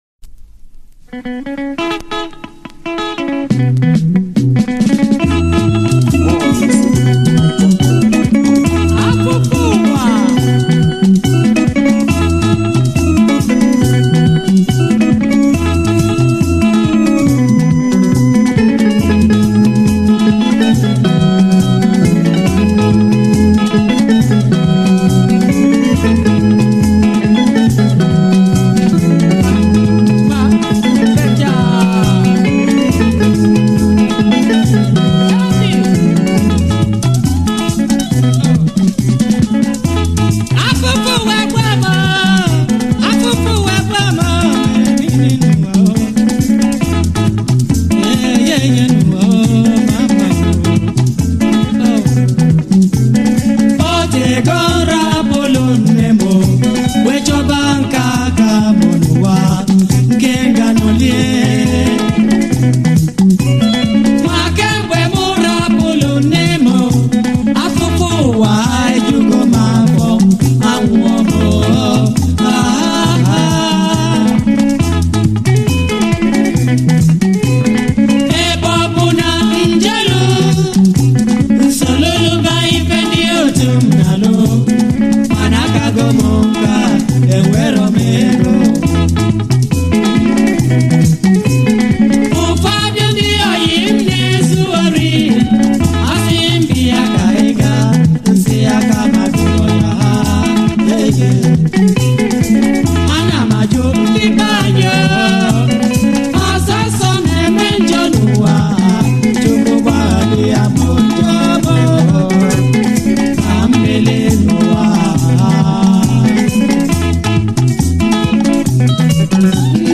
Home » Highlife